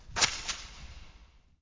科幻外星空间模拟 " 人工模拟的空间声音 11
描述：人工模拟空间声音 通过处理自然环境声音录制而创建Audacity
Tag: 实验 飞船 声景 环境 科幻 无人驾驶飞机 航天器 外星人 人造的 效果 UFO FX 空间 科幻 UFO 气氛